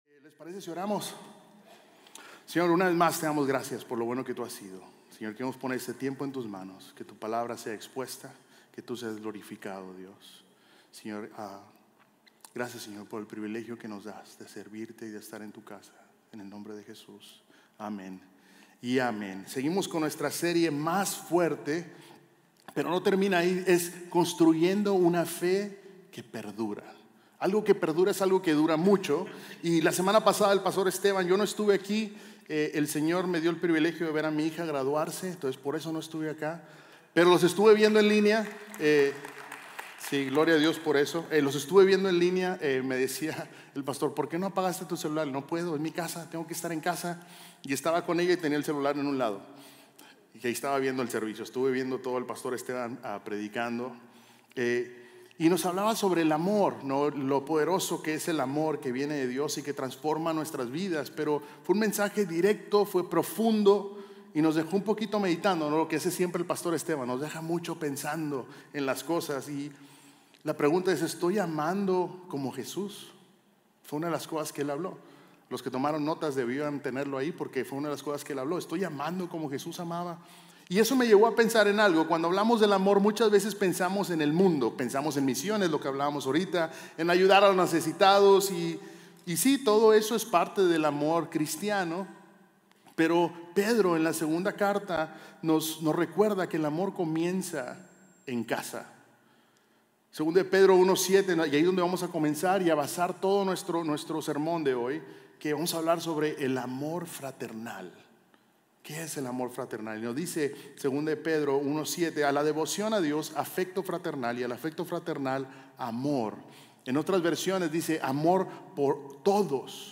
Sermones North Klein – Media Player